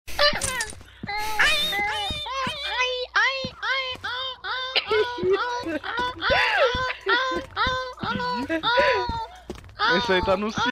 Play, download and share botaozinho do black original sound button!!!!
cachorro-chorando.mp3